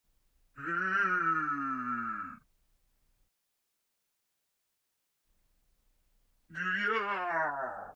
ゴブリン1 （低音）